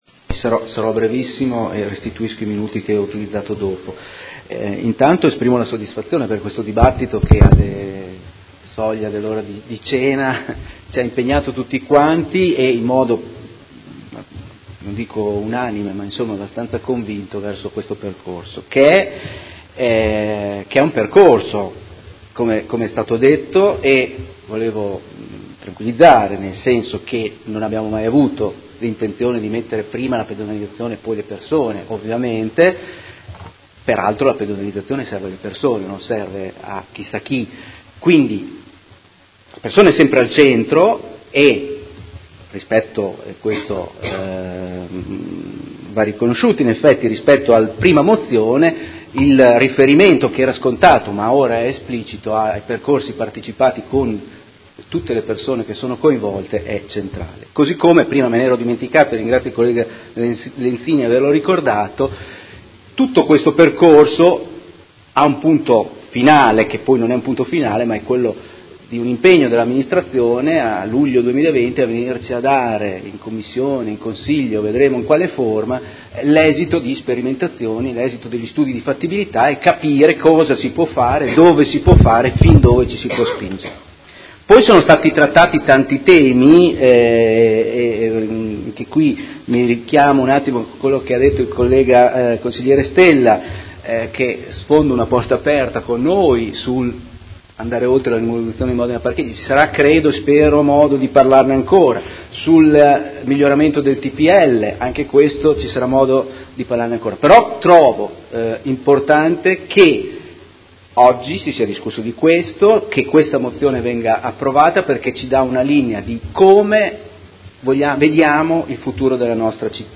Giovanni Silingardi — Sito Audio Consiglio Comunale